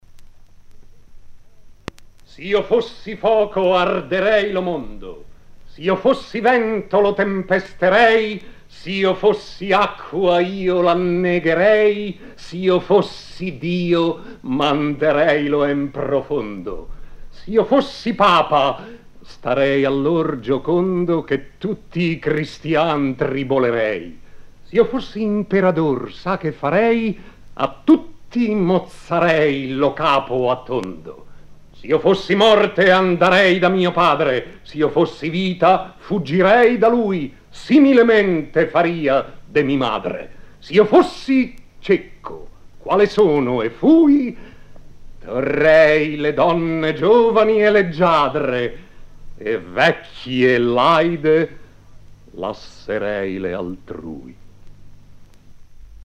Vittorio Gassman recita: